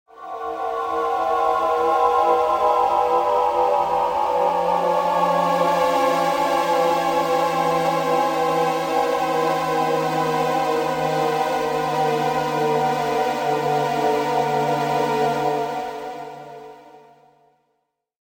SFX惊悚恐怖悬疑的气氛音效下载